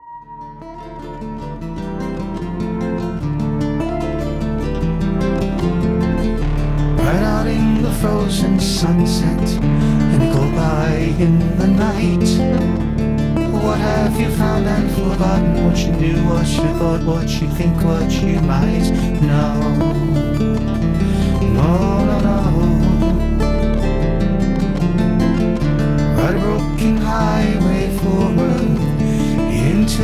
Synthesizer
Acoustic guitar, Cello